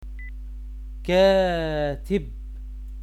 This lesson is for students who interests in learning Arabic Language for practicing politics or international press in Arabic . it contains 30 of the most common words in the field of The political field in Arabic. you can read and listen the pronunciation of each word .